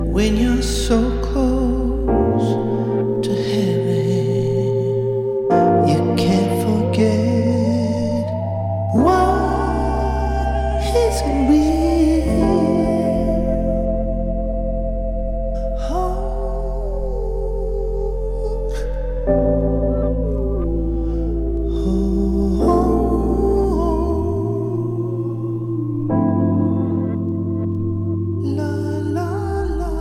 Soul R&B